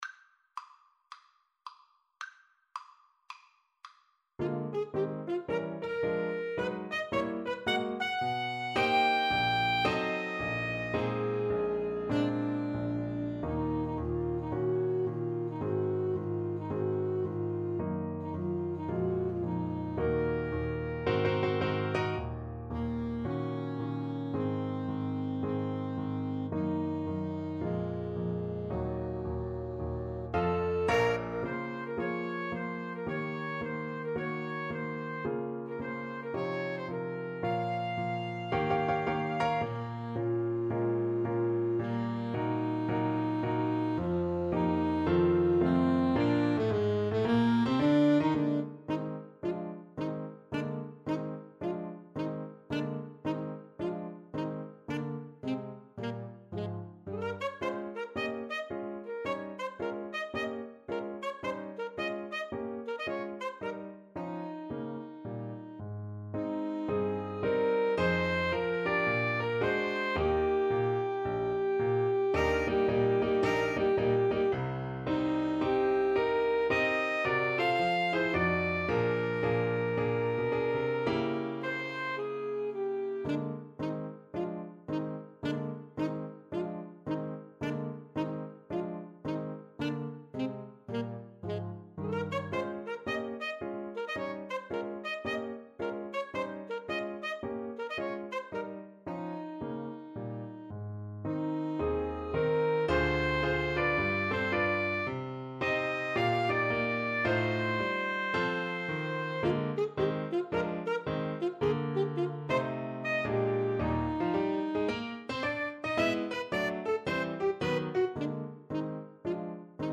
Moderato =110 swung